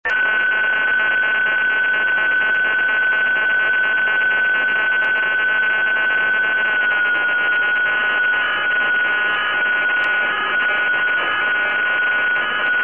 Sound of interference on 432,015